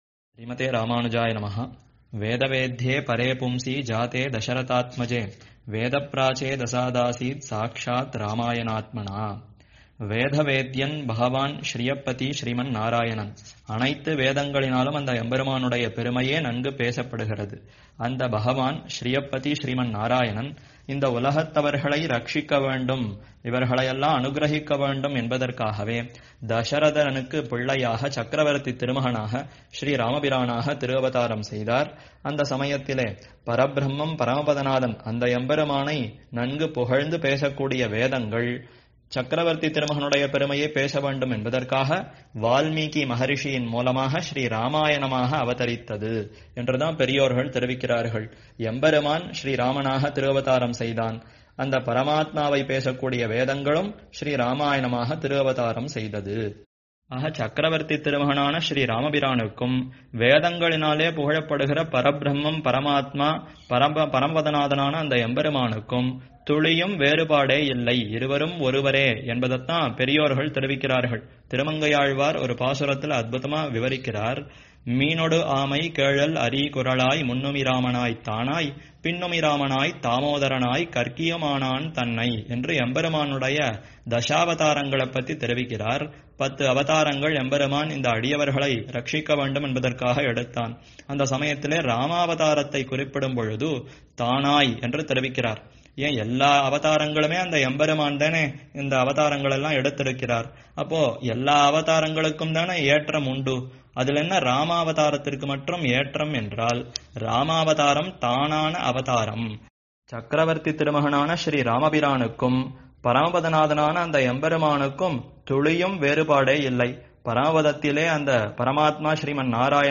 2023-Sobakruth Year – Aavani Month Upanyasangal – Abayapradhana Saram Avatharikai